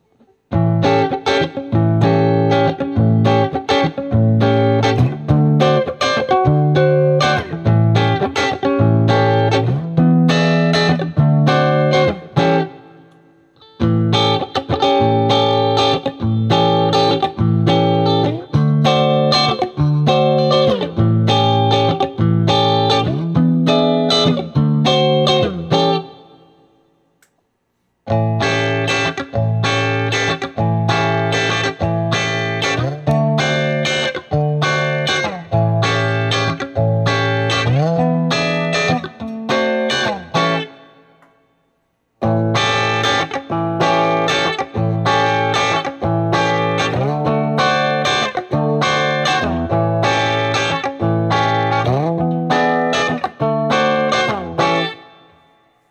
Each recording goes though all of the pickup selections in the order: neck, both (in phase), both (out of phase), bridge.
Tweed 7th Chords
I recorded the difference with the second set of recordings with the pickups farther from the strings.